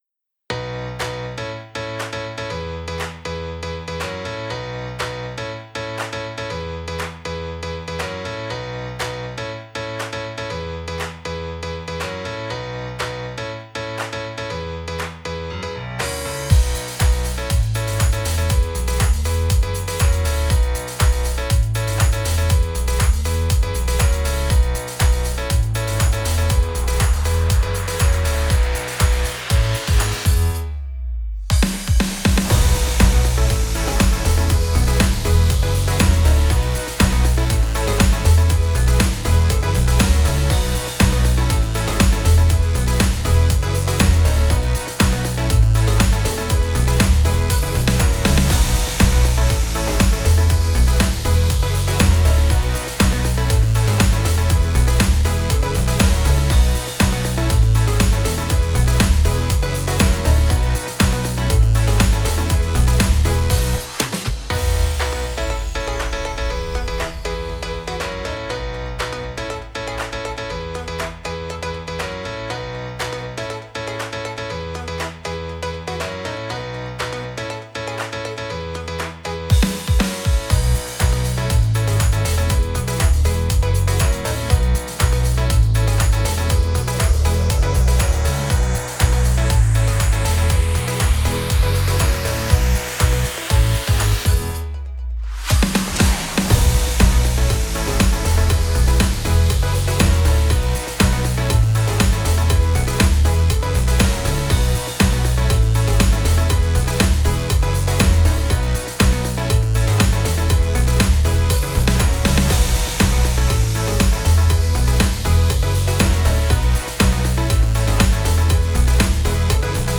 どこか不思議な雰囲気のある、かっこいいゲーム風BGMです
※コチラは過去の作品を作り直した「リミックス版」です
▶メロディなし版